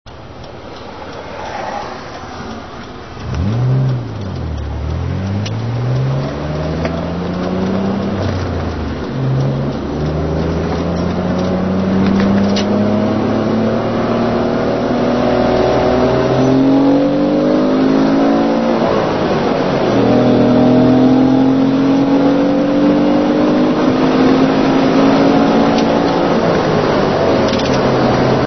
Hi quailty sound file recorded from inside the car, windows closed.
windowsclosed2.mp3